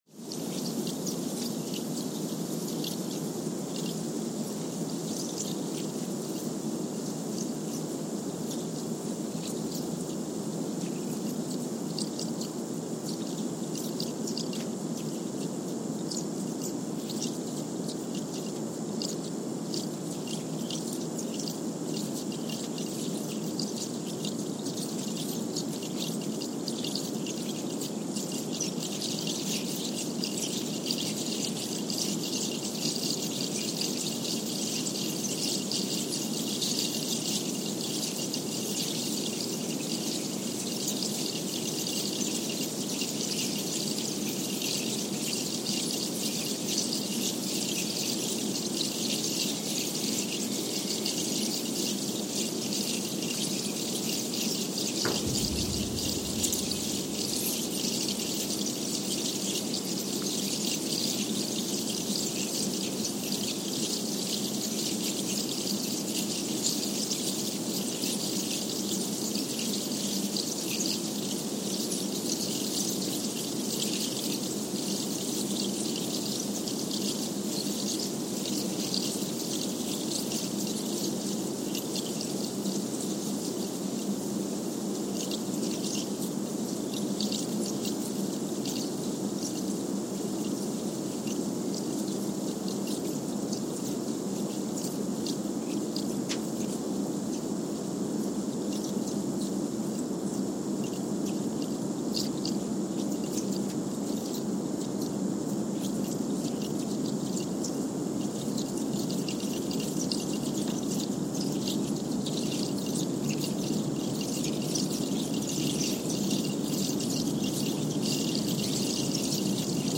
San Juan, Puerto Rico (seismic) archived on February 11, 2023
Sensor : Trillium 360
Speedup : ×1,000 (transposed up about 10 octaves)
Loop duration (audio) : 05:45 (stereo)
SoX post-processing : highpass -2 90 highpass -2 90